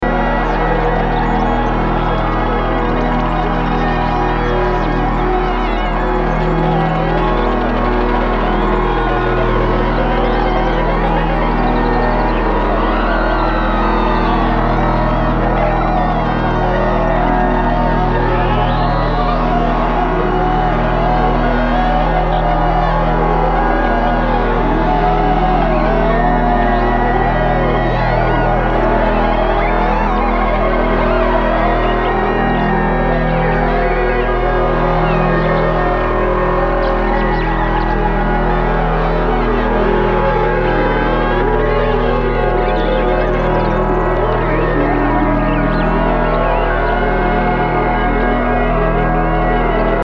Experimental >
Ambient, Drone >